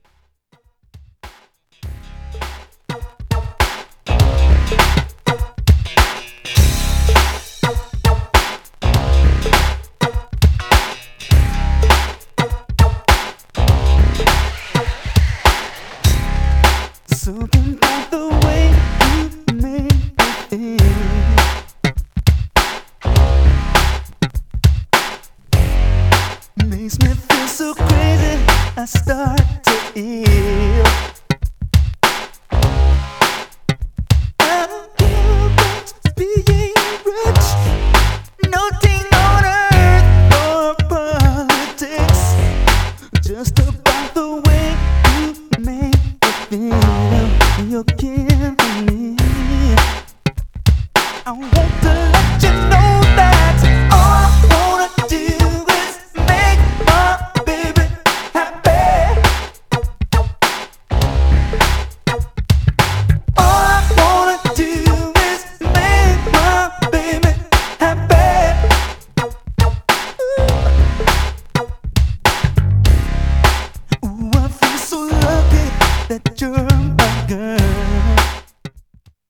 Styl: Disco, Breaks/Breakbeat Vyd�no